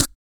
MACHINELIPS1.wav